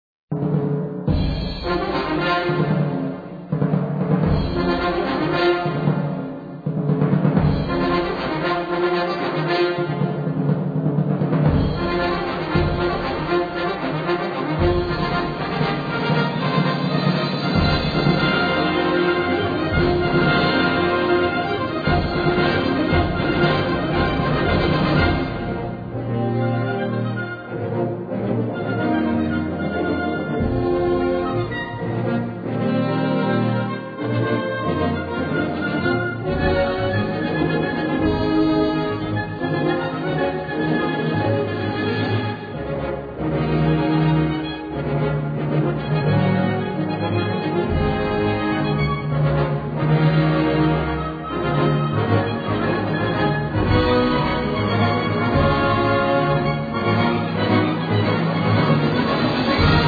Gattung: Konzertmarsch
Besetzung: Blasorchester
Das Hauptmotiv G-A-D-F-G beherrscht das ganze Werk.